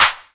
CLAP DM.11.wav